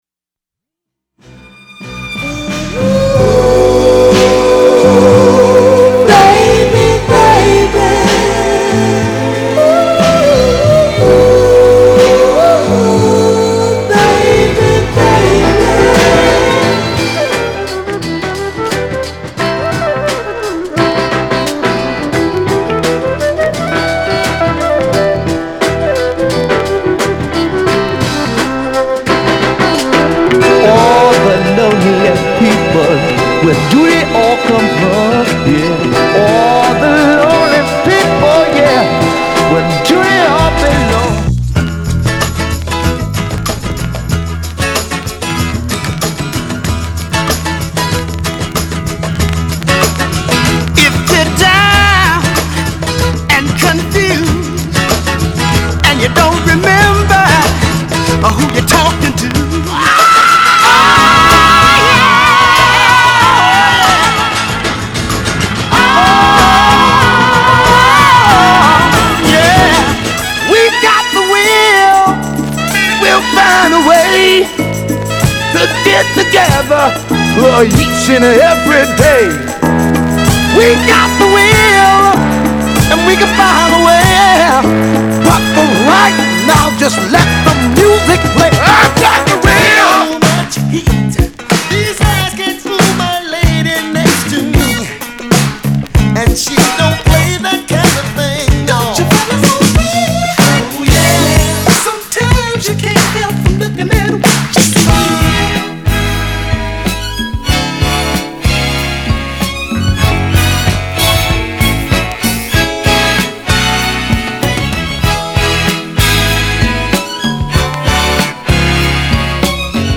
R&B、ソウル